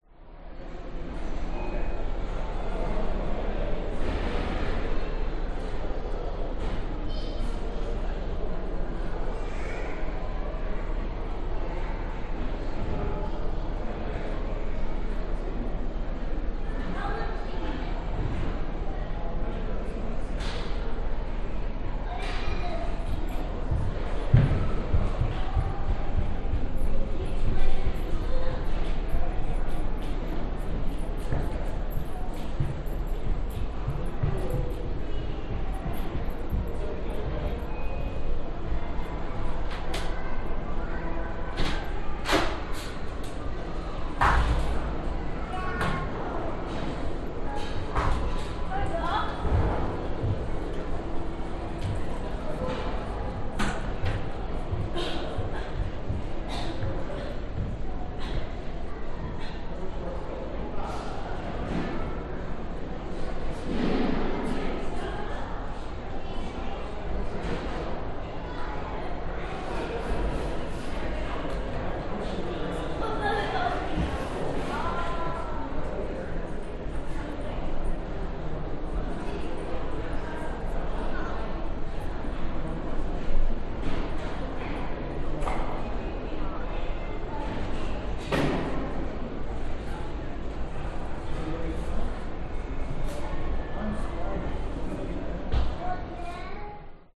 Здесь собраны атмосферные записи: от шороха шагов по паркету до приглушенных разговоров в выставочных залах.
Звук в музее Брисбена, внутренний, возле лестницы, лифта и туалетов